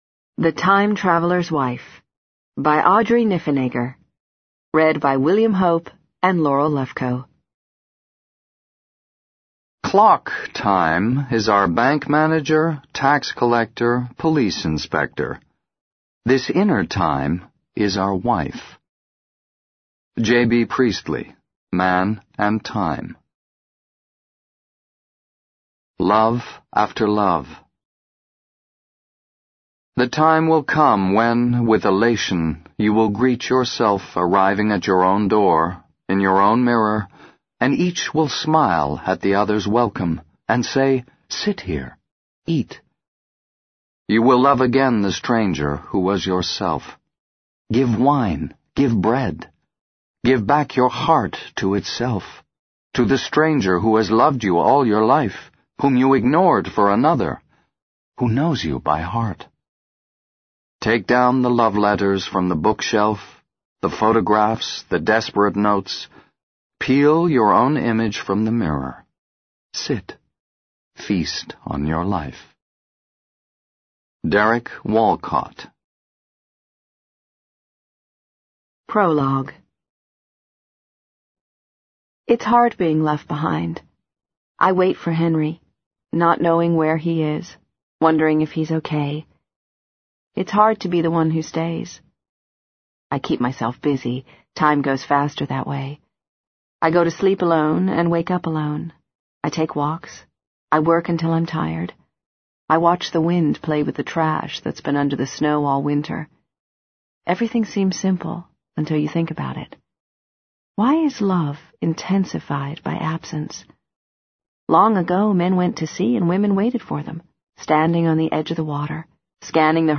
在线英语听力室【时间旅行者的妻子】01的听力文件下载,时间旅行者的妻子—双语有声读物—英语听力—听力教程—在线英语听力室